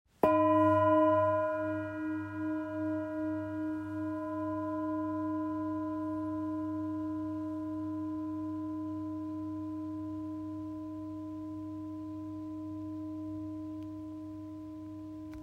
Master Series Singing Bowls 30 – 33cm
33cm Stick
Every Master Series Singing Bowl is made to deliver a powerful, harmonious sound.
With clarity, warmth, and a long-sustaining tone, these bowls create an enveloping resonance that enhances meditation, sound baths, and therapeutic sessions.